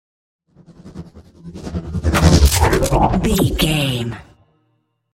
Sci fi hit technology electricity
Sound Effects
heavy
intense
dark
aggressive
hits